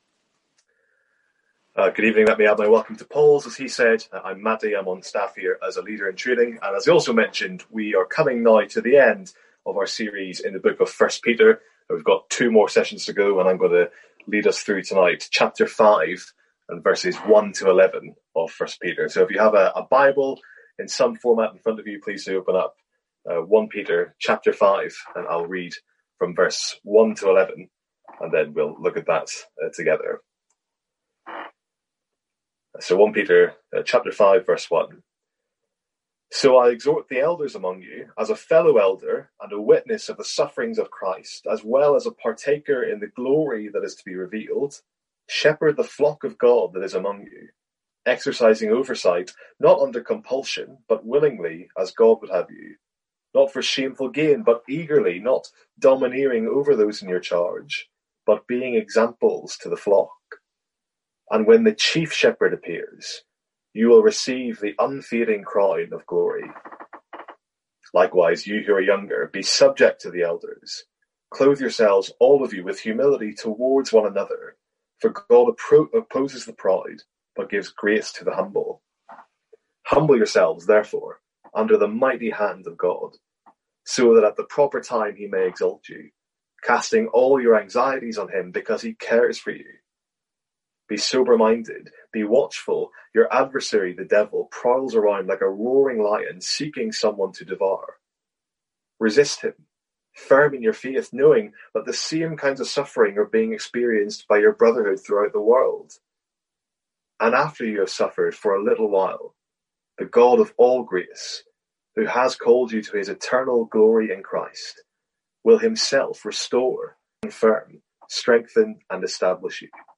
Sermons | St Andrews Free Church
From our evening series in 1 Peter.